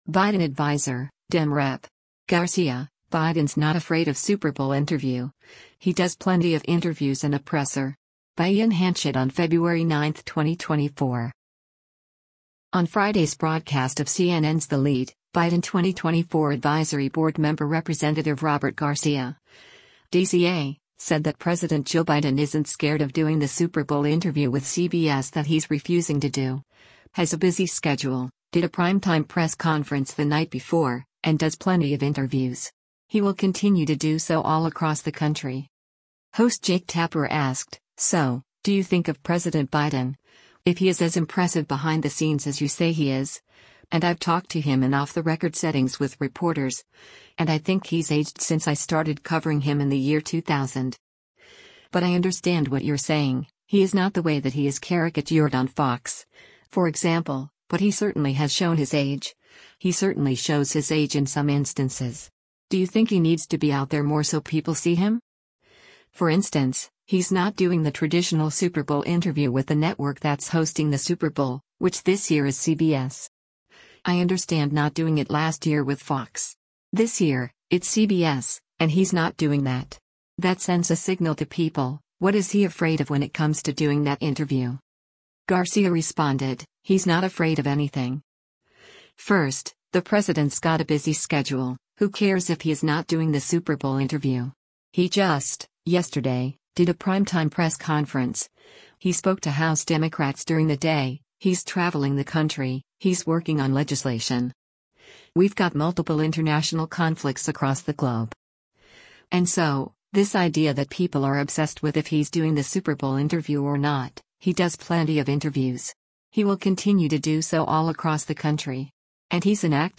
On Friday’s broadcast of CNN’s “The Lead,” Biden 2024 Advisory Board member Rep. Robert Garcia (D-CA) said that President Joe Biden isn’t scared of doing the Super Bowl interview with CBS that he’s refusing to do, has “a busy schedule,” “did a primetime press conference” the night before, and “does plenty of interviews.